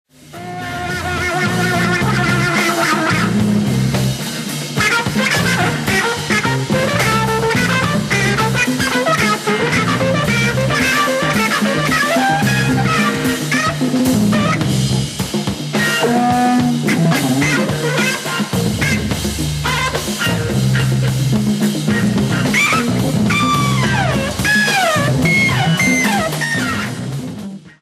LIVE AT PHILHARMONIE, BERLIN 11/01/1973
SOUNDBAORD RECORDING
- "MONO EDITION" -